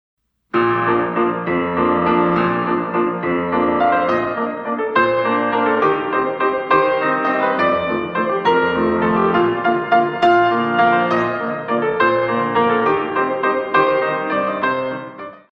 In 3
32 Counts